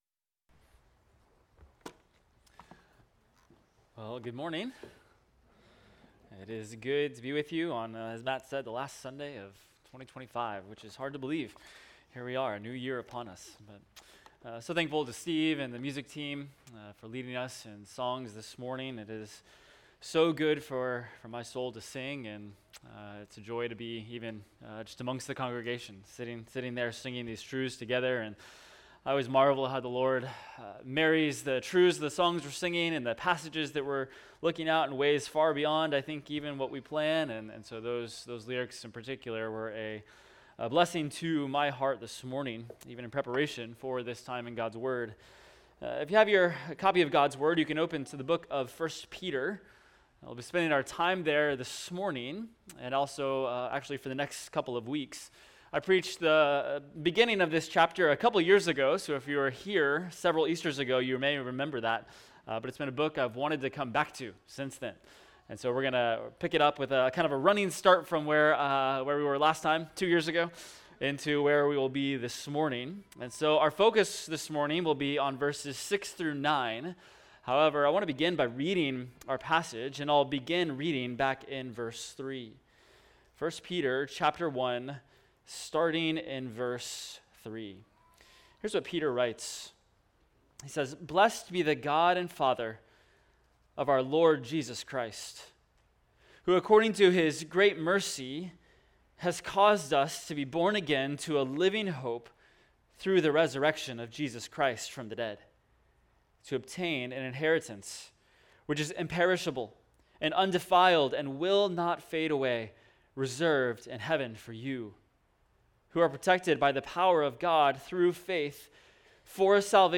Expository Preaching from the Gospel According to Luke – Luke 10:38-42 One Thing Needful